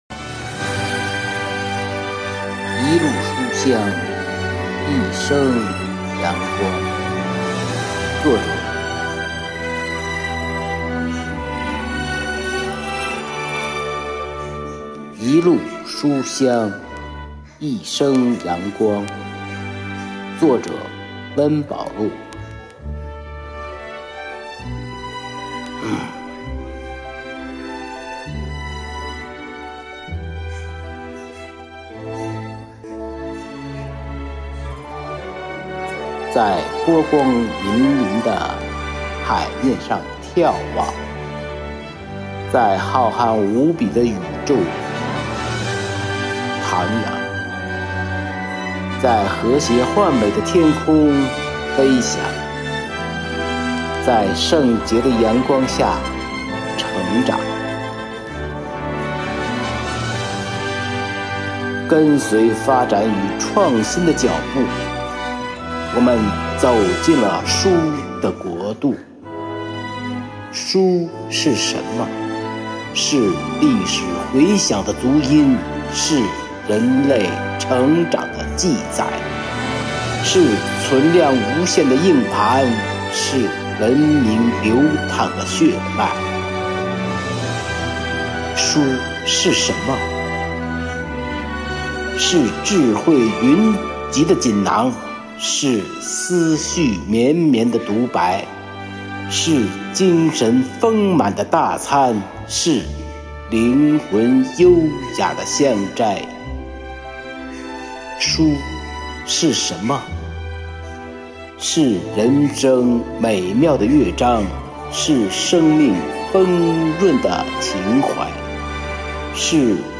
暨诵读心声支队第1场幸福志愿者朗诵会